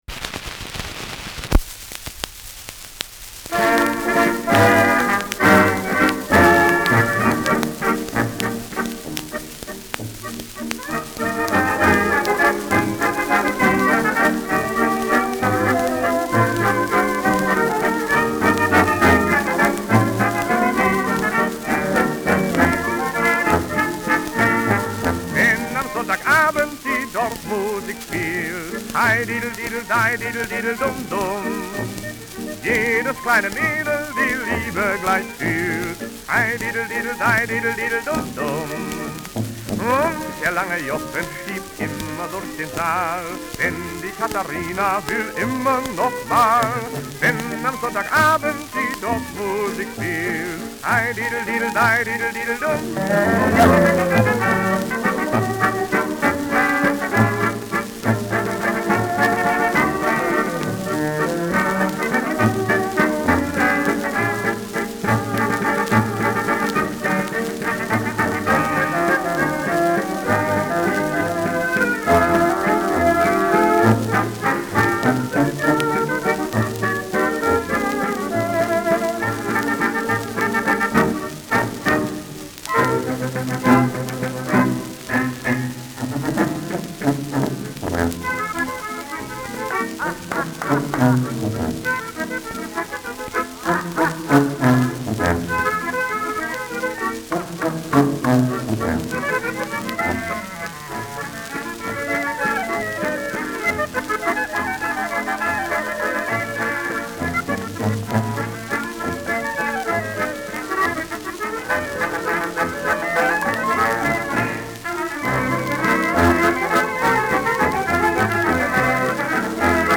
Schellackplatte
Mit Gesang.
[Berlin] (Aufnahmeort)